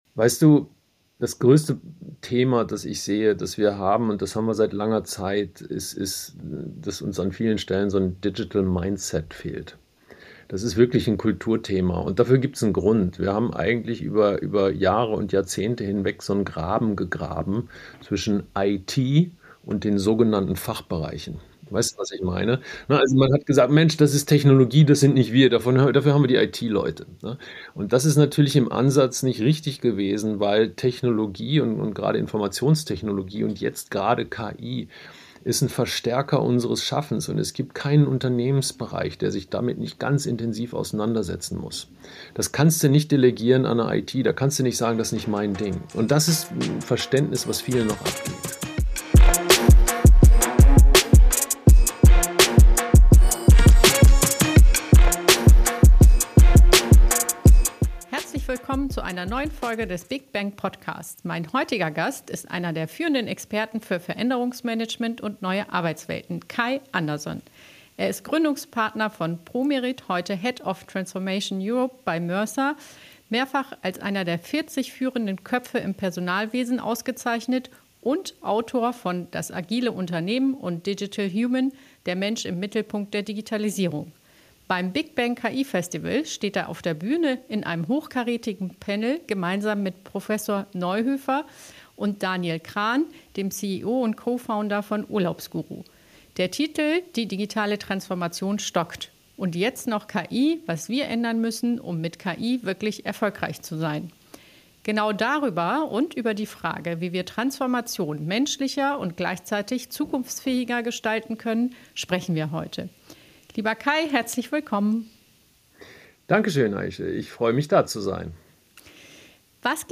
Ein Gespräch voller Klartext, Impulse und Inspiration – für alle, die Transformation erfolgreich gestalten wollen.